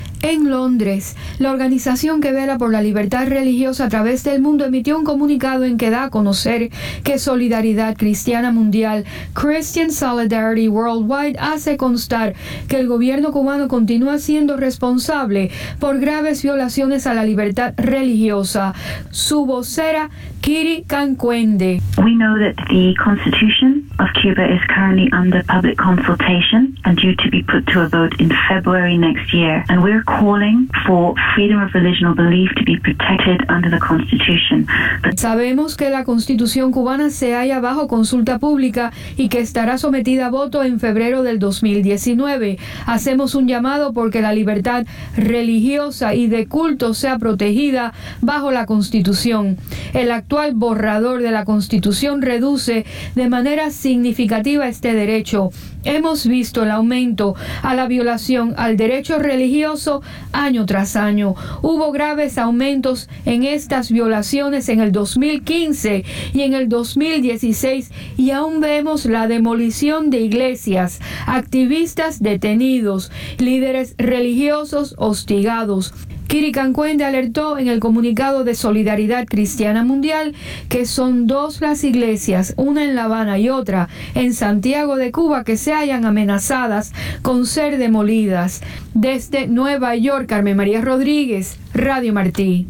Comunicado